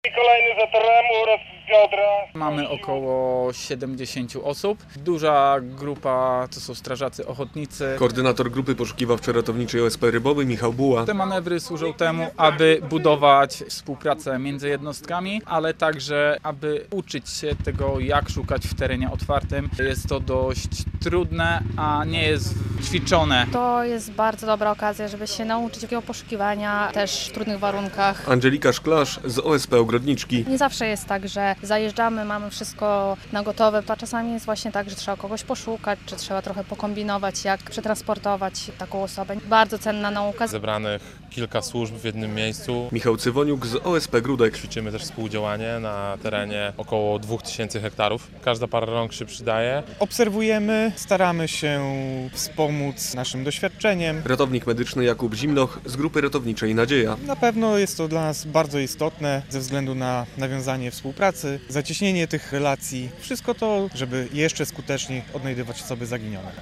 Manewry poszukiwawczo-ratownicze Obóz 2025 - relacja